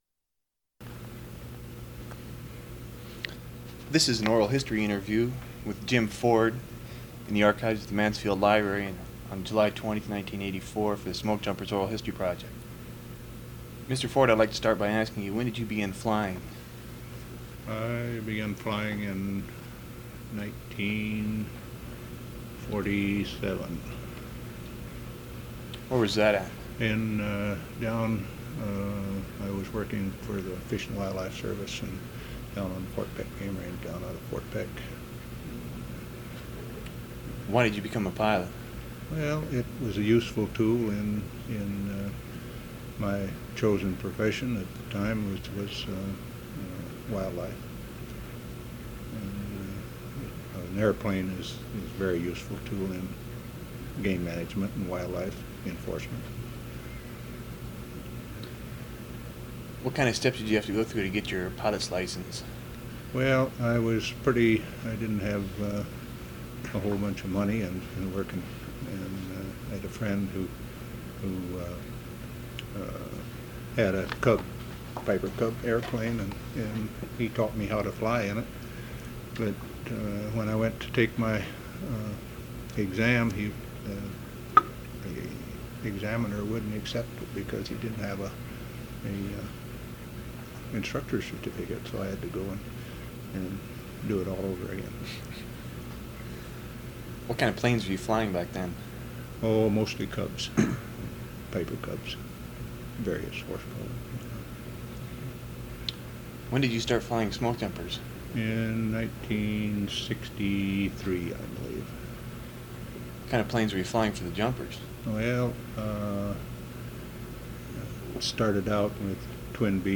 Document Type Oral History
Original Format 1 sound cassette (50 min.) : analog